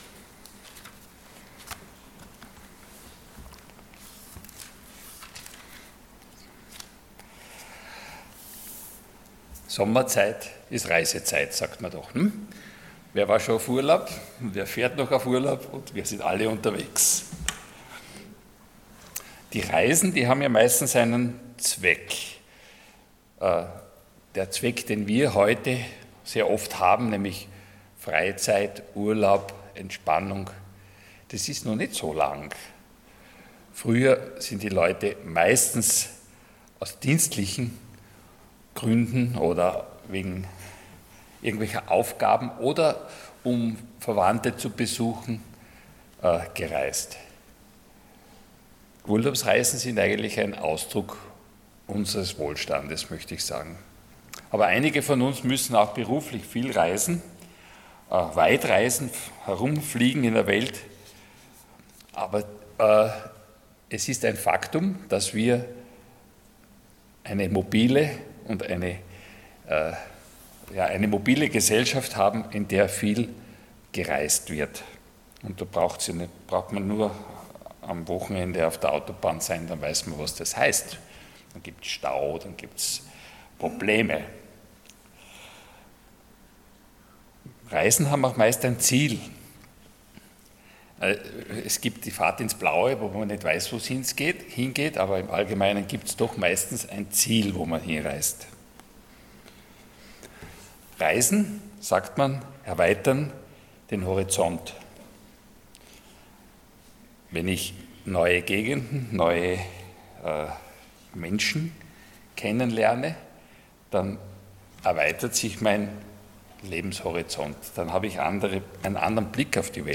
Passage: Römer 15,22-33 Dienstart: Sonntag Morgen